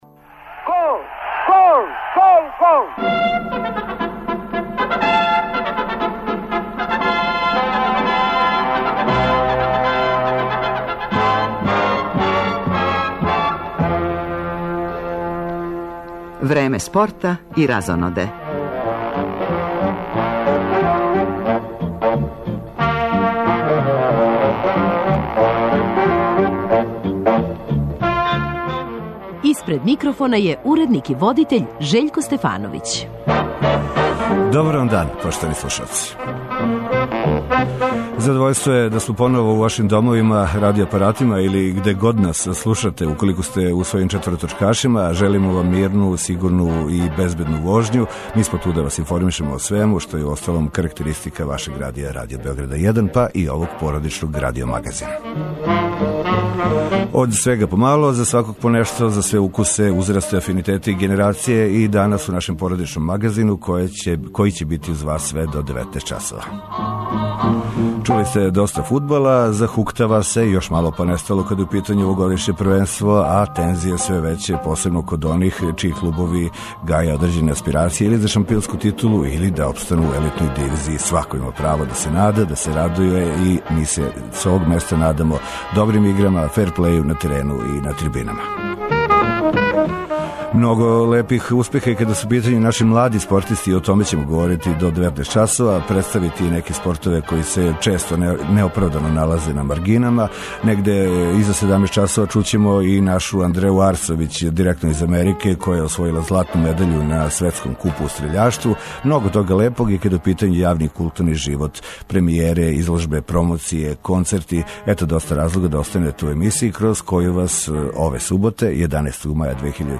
Почетак емисије резервисан је за јављање репортера са фудбалске утакмице Раднички-Партизан, а доцније ћемо пратити и остале сусрете 27. кола Супер лиге Србије, Прве лиге Србије, као и важније интернационалне клупске мечеве.